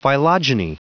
Prononciation du mot phylogeny en anglais (fichier audio)
Prononciation du mot : phylogeny